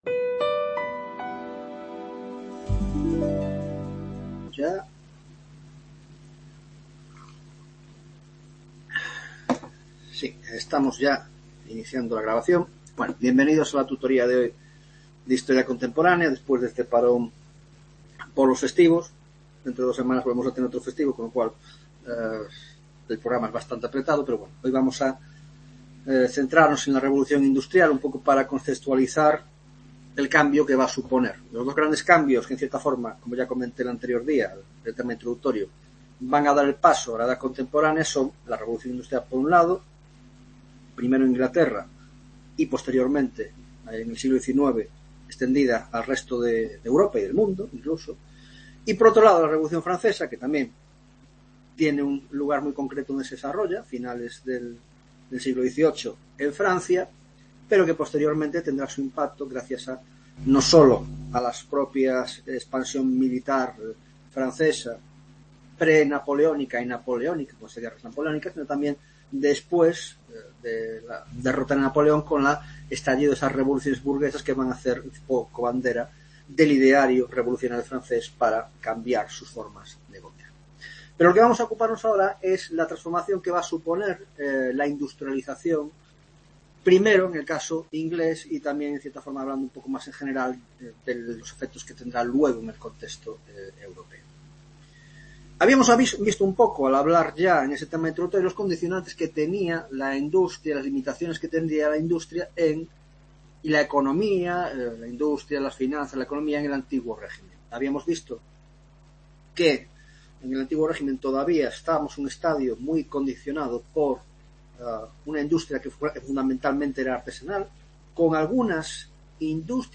2ª tutoria de Historia Contemporánea - Revolución Industrial